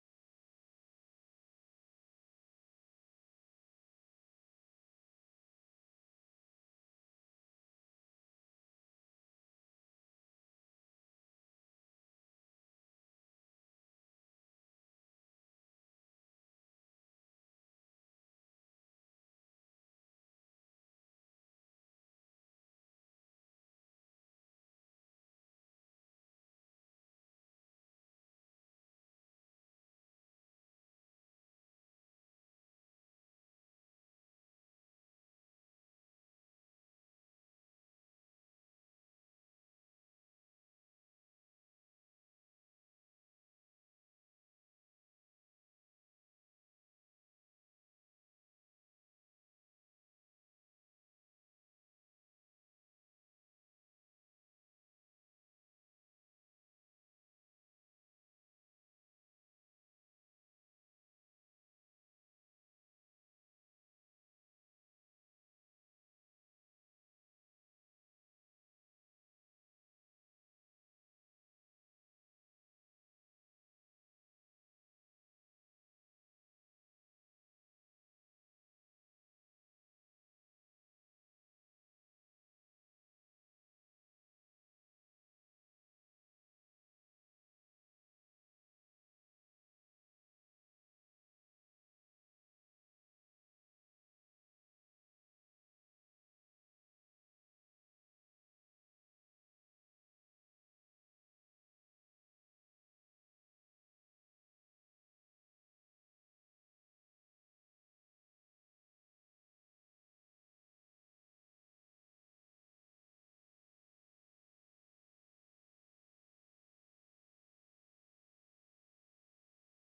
December 1st 2024 Praise and Worship at FWC
Praise Worship